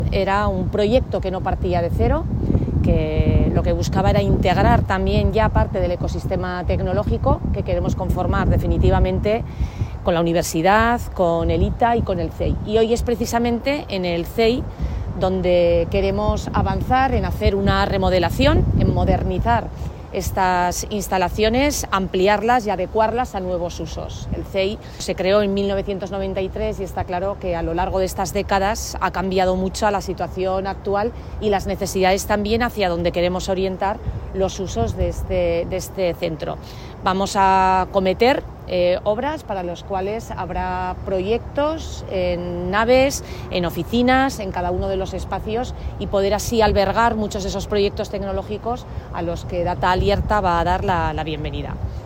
Mar Vaquero habla del DAT Alierta y de la renovación del CEEI
Vicepresidenta y consejera de Presidencia, Economía y Justicia